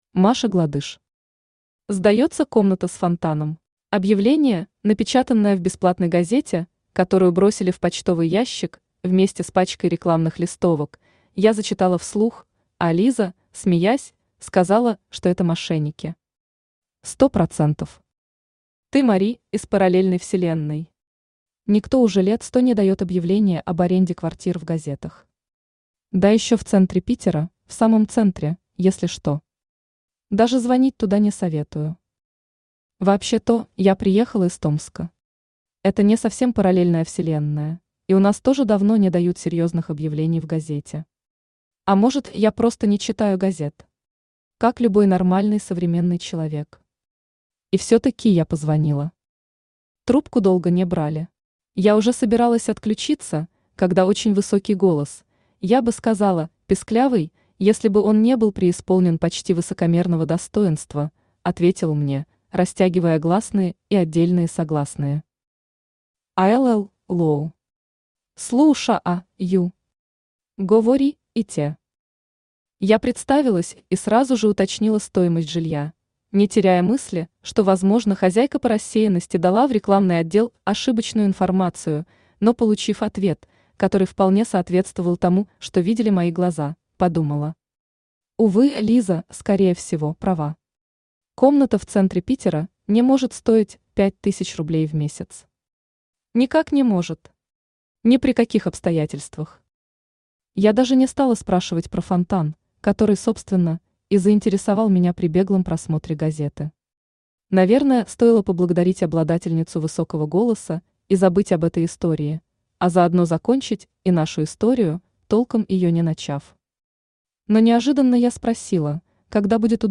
Аудиокнига Сдается комната с фонтаном | Библиотека аудиокниг
Aудиокнига Сдается комната с фонтаном Автор Маша Гладыш Читает аудиокнигу Авточтец ЛитРес.